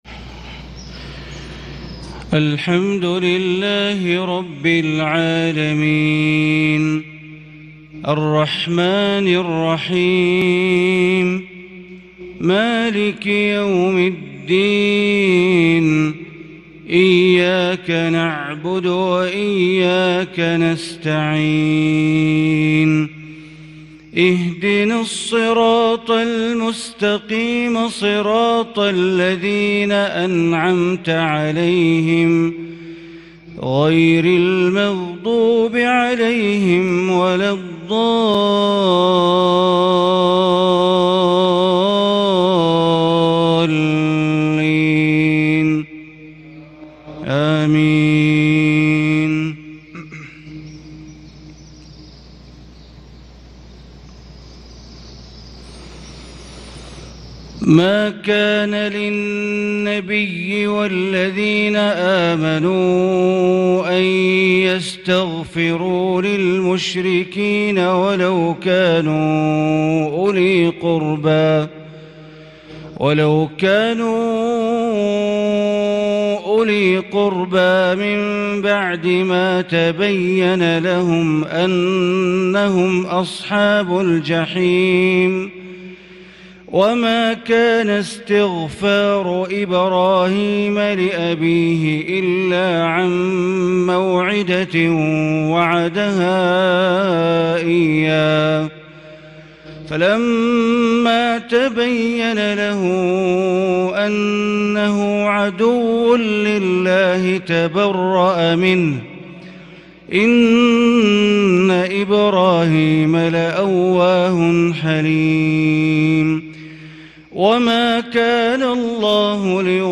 صلاة الفجر ١-٦-١٤٤٢هـ من سورة التوبة > 1442 هـ > الفروض - تلاوات بندر بليلة